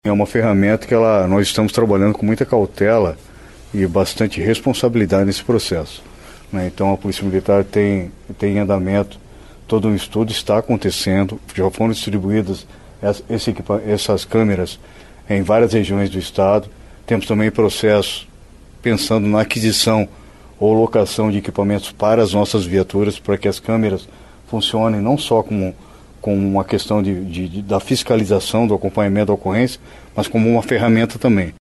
O secretário de Estado de Segurança Pública do Paraná, Hudson Leôncio Teixeira, disse em entrevista coletiva, nesta quarta-feira (19), que os estudos para a possível implementação de câmeras em fardas e viaturas de policiais devem ser concluídos em agosto deste ano.